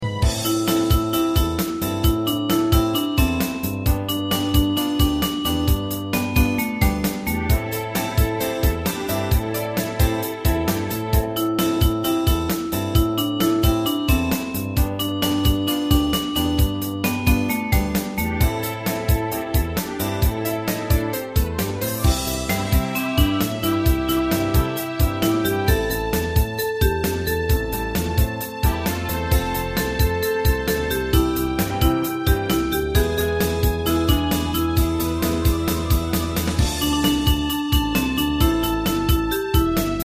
Unison musical score and practice for data.